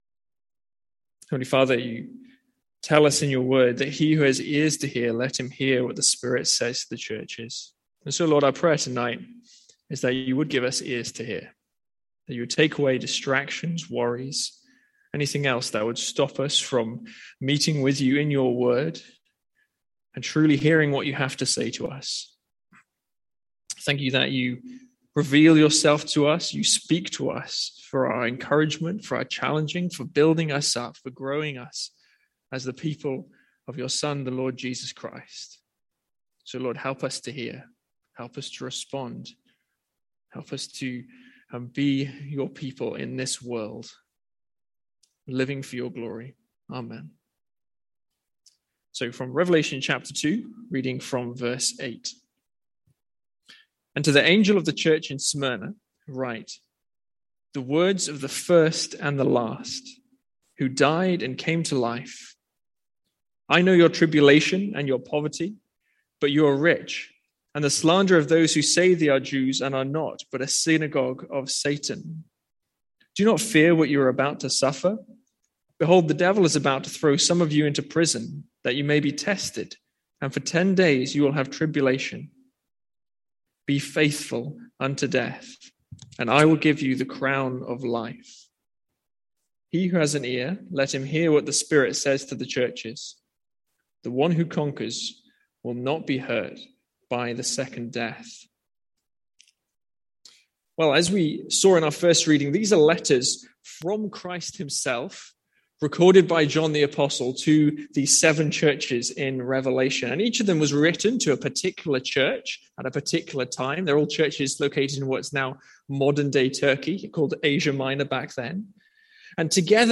Sermons | St Andrews Free Church
From our evening series in Revelation.